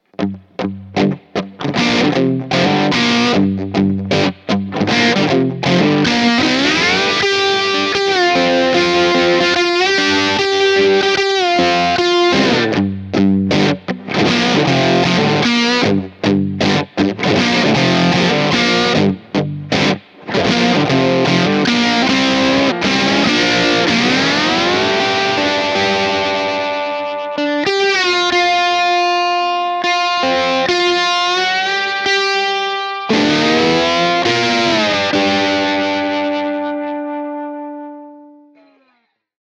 Tutte le clip audio sono state registrate con testata a valvole artigianale e cassa 2×12 equipaggiata con altoparlanti Celestion Creamback 75 impostato su un suono estremamente clean.
Chitarra: Gibson Les Paul (pickup al ponte)
Genere: Southern Rock
Boost: 8/10
Twang: 6/10
Blackface Output: 4/5
Southern-LP.mp3